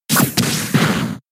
Horn Attacke
horn-attacke.mp3